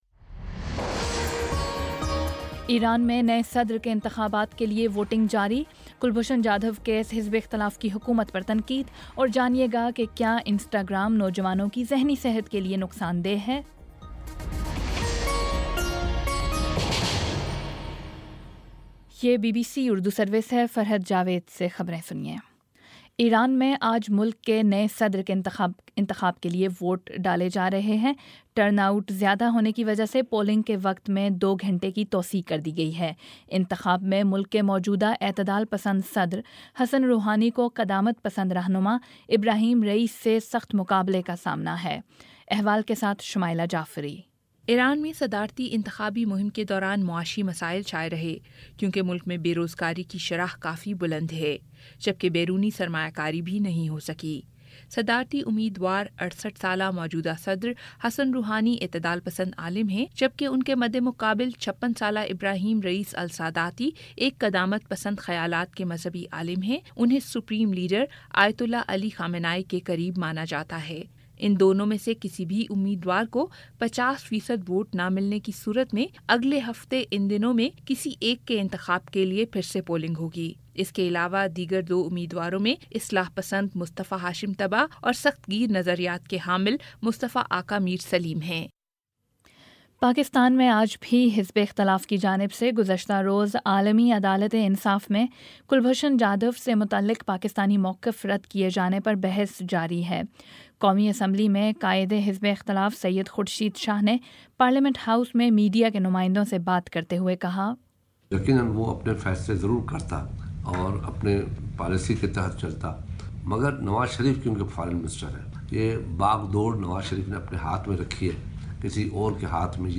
مئی 19 : شام سات بجے کا نیوز بُلیٹن